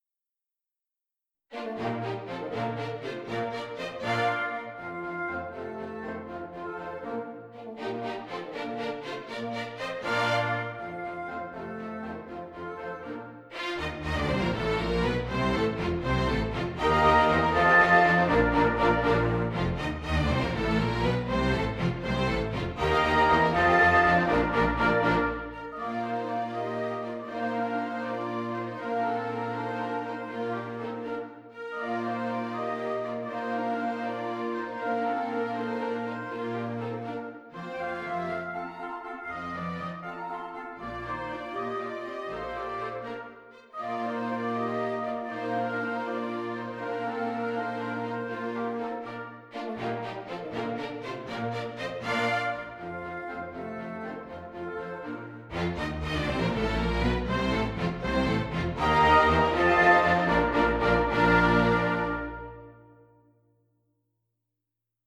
I did now the same with the first 5 dances on the basis of these old midi files by simplifying them a bit and using my tool NotePerformer.
My renditions may have too much reverb, should I reduce the hall effect?
WoO13-2_orch.mp3